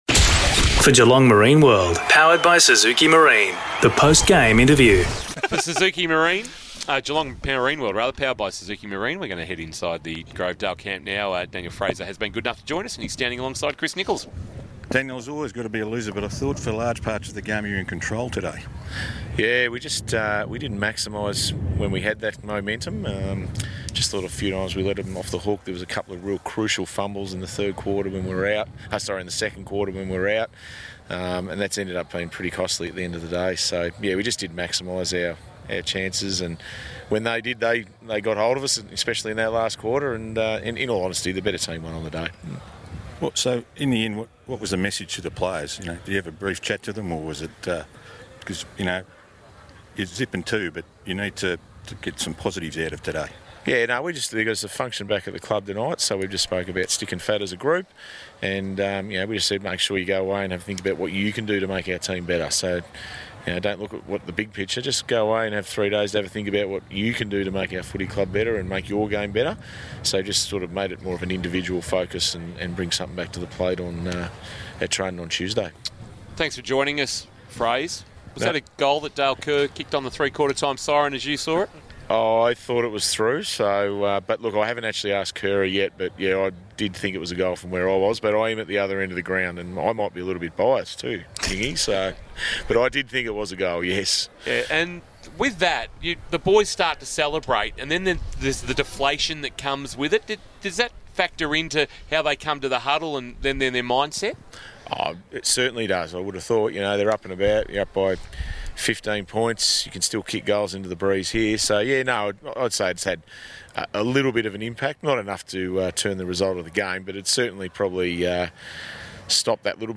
2022 - GFL ROUND 2 - GEELONG WEST vs. GROVEDALE: Post-match Interview